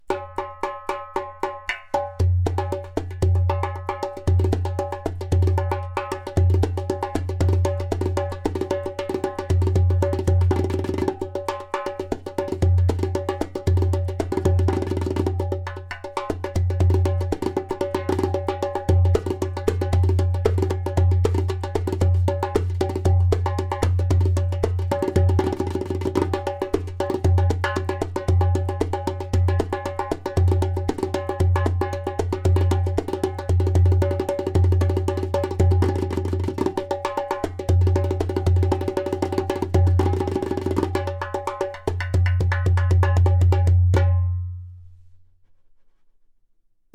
P r e m i u m Line Darbuka
In this exclusive line, materials such as clay, glaze, and natural goat skin come together in a magical harmony, giving life to a balanced, resonant sound.
• High sound clear “taks”.
• Deep bass
• Very strong clay “kik”/click sound